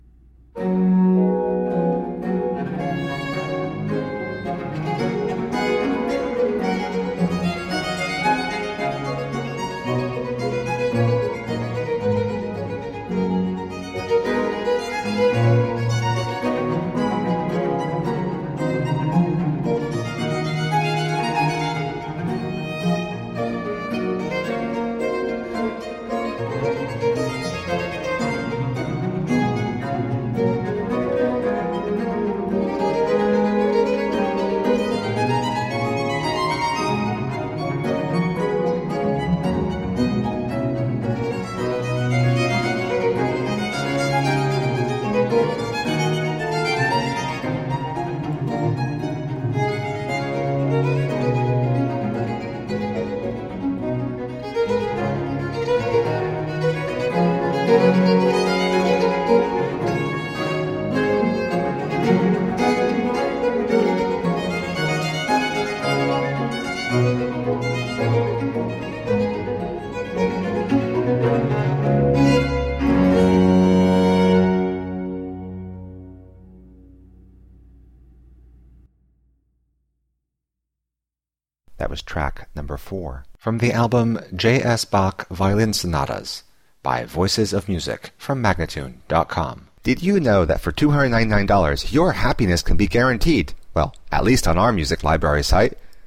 Lilting renaissance & baroque vocal interpretations .
Classical, Chamber Music, Baroque, Instrumental, Cello